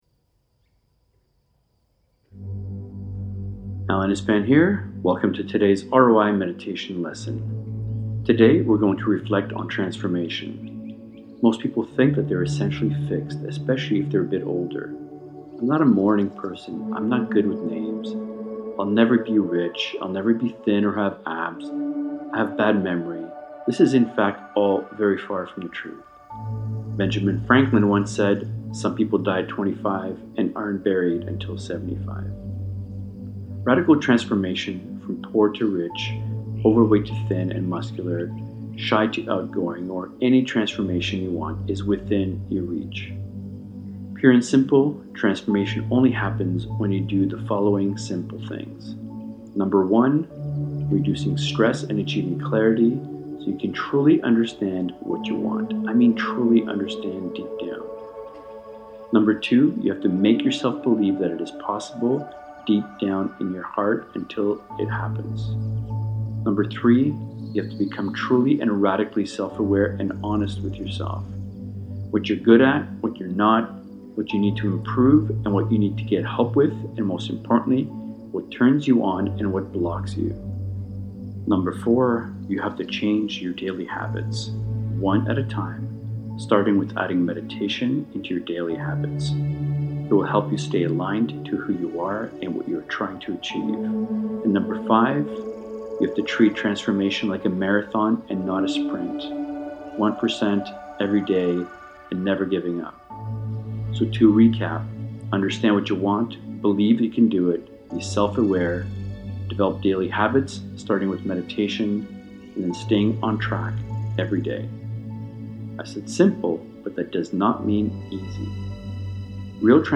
Transformation - ROI Meditation Lesson